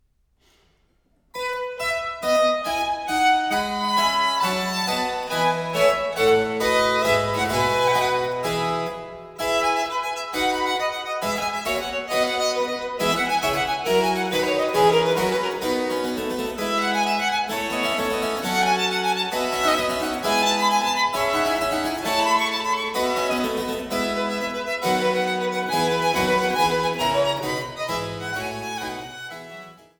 Die wohl bekanntesten Violinsonaten des 18. Jahrhunderts